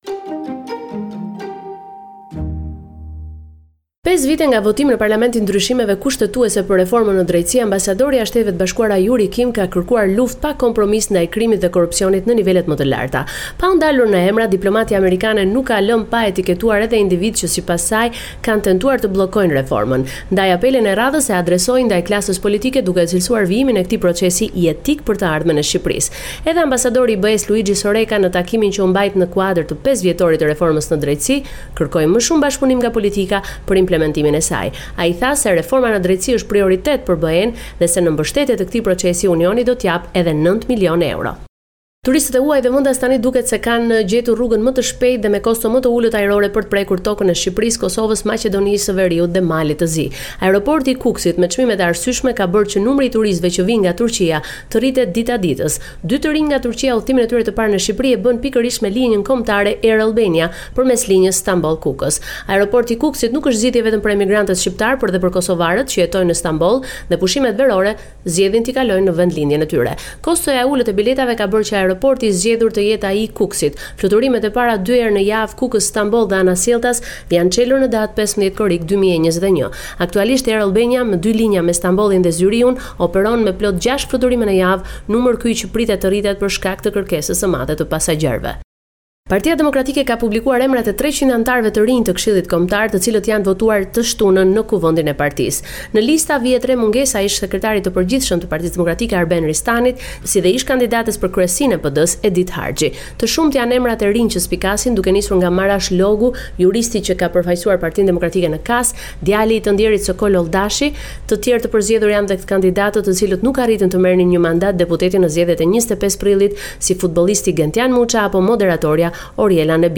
Raporti me te rejat me te fundit nga Shqiperia.